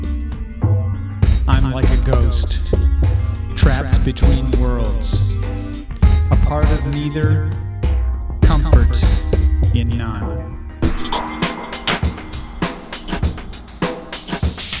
So I started creating musical settings with loops for my own works.
All samples are reduced quality versions of the full audio available on the CD.
Spoken Word